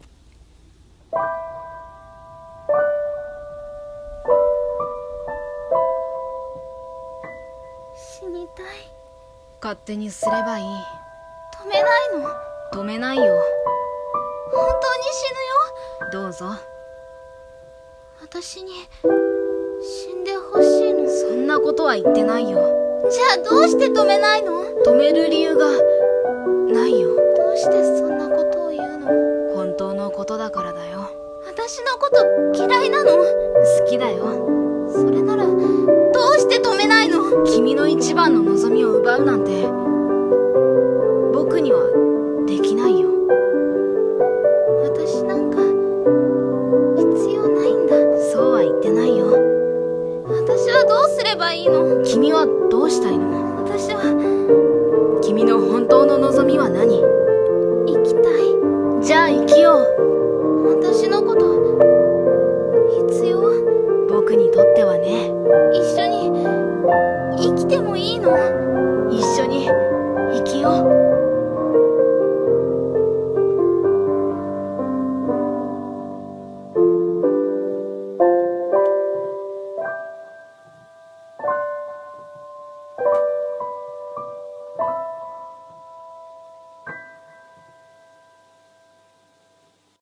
声劇「死にたい。」